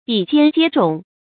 注音：ㄅㄧˇ ㄐㄧㄢ ㄐㄧㄝ ㄓㄨㄙˇ
比肩接踵的讀法